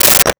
Telephone Receiver Down 02
Telephone Receiver Down 02.wav